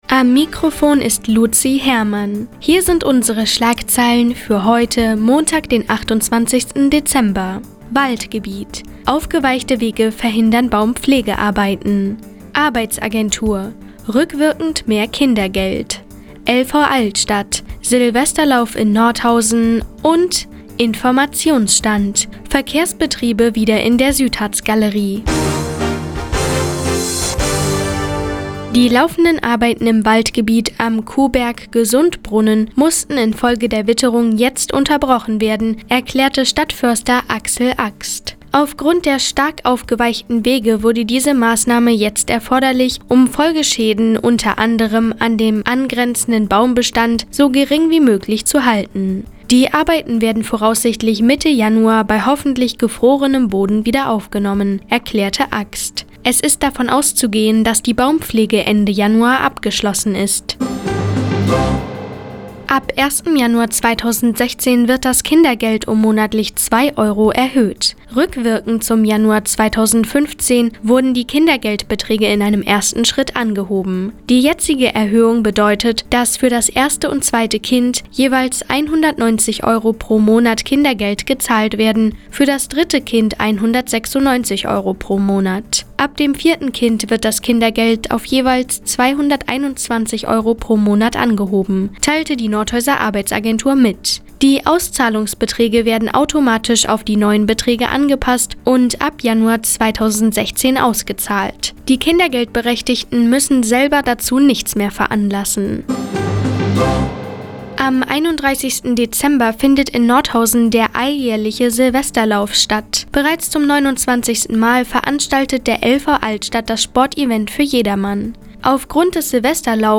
Die tägliche Nachrichtensendung des OKN ist jetzt hier zu hören...